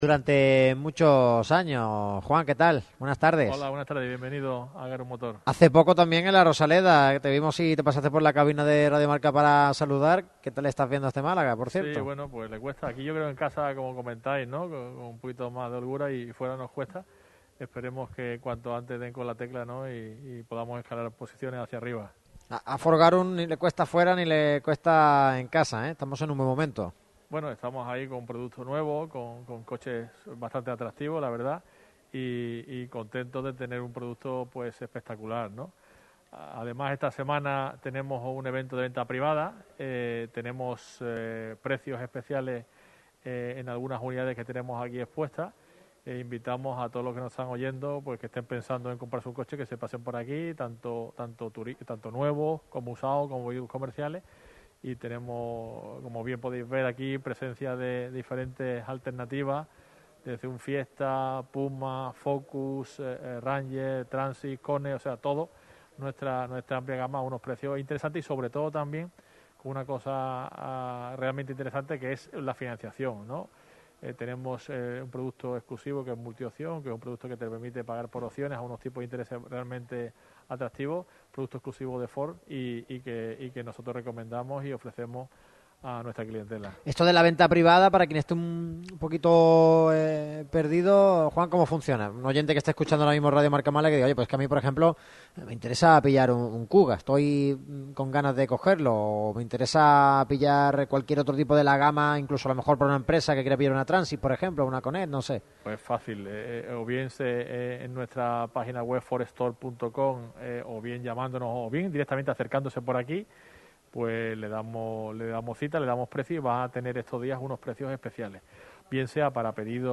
La radio que vive el deporte se trasladó, este martes 9 de noviembre, a Ford Garum Motor para realizar su habitual programa.
Ford Garum Motor acogió a Radio Marca Málaga para hablar sobre la actualidad del deporte malagueño y repasar las mejores ofertas cualidades de sus vehículos.